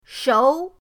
shou2.mp3